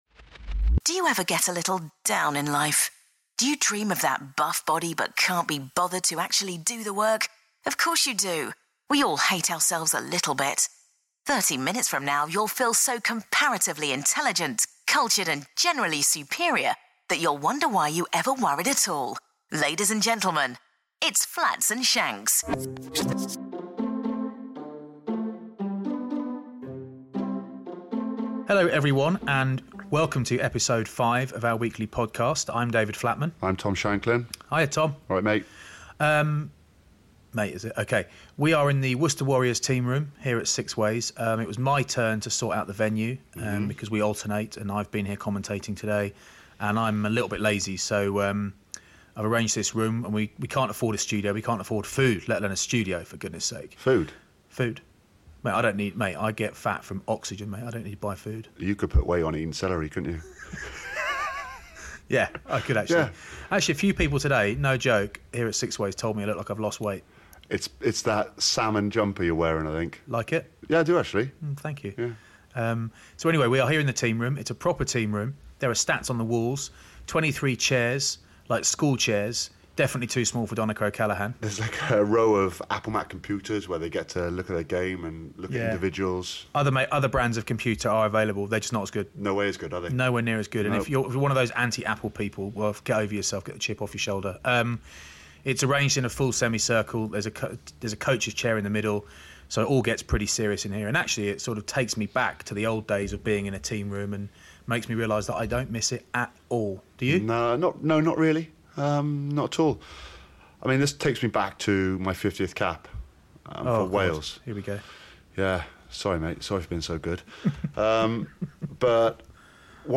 This week the chaps go back to their roots, talking rugger in a proper team room in the West Midlands.